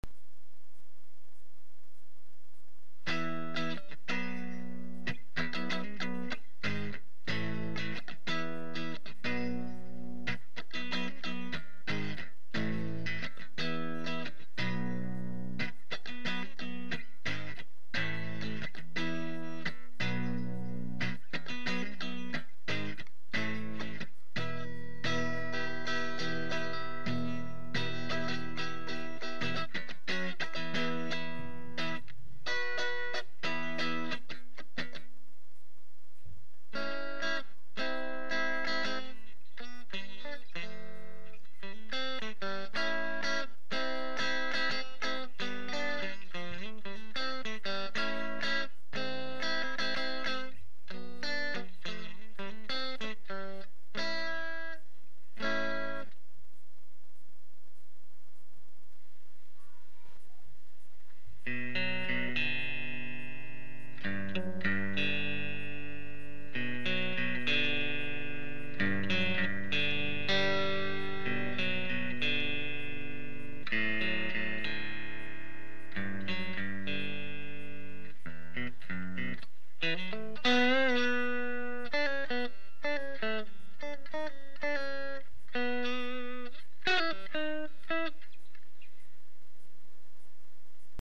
I plugged the head into a Traynor Bass cab with a single 8ohm 15 speaker
No effects used, natch!
Reminder: These were recorded after using an attenuator into a 15" speaker.
TC-15-Clean-UnderBridge.mp3